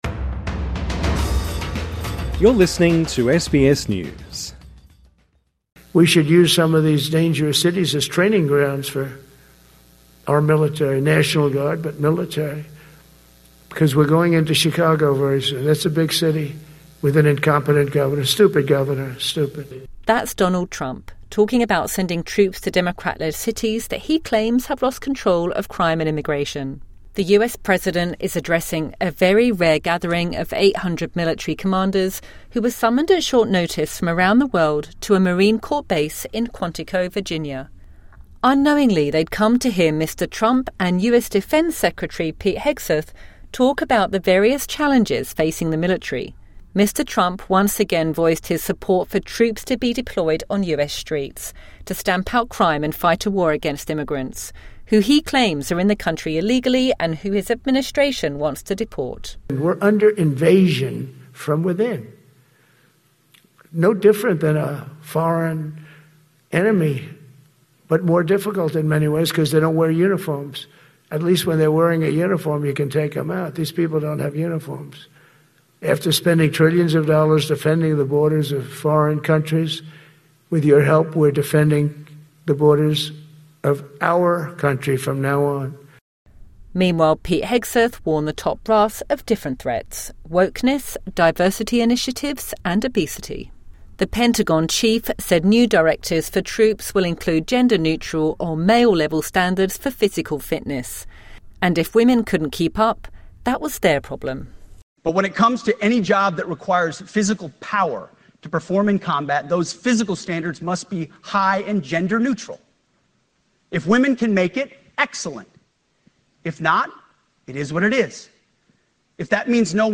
When hundreds of military commanders were ordered to attend a last-minute meeting in Virginia in the United States, there was speculation of a major announcement. What greeted them was US President Donald Trump and defence secretary Pete Hegseth delivering an impassioned speech on "wokeness", obesity and using US cities as military training grounds.